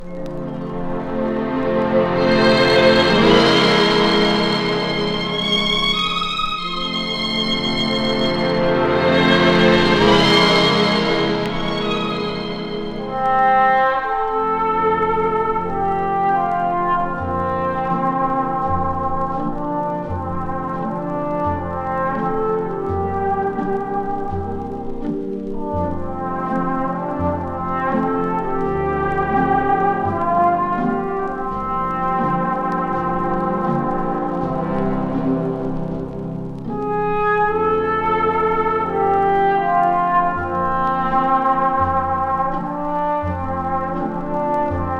Jazz, Pop, Stage & Screen　USA　12inchレコード　33rpm　Mono